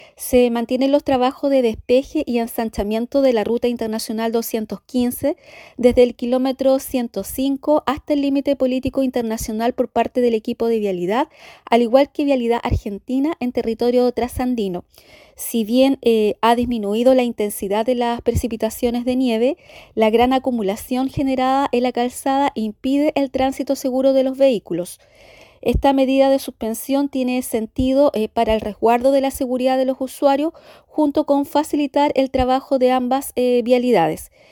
Sobre las complicaciones y las suspensión del tránsito, fue la delegada presidencial de Osorno, Claudia Pailalef, quien más temprano entregó detalles.
delegada-miercoles.mp3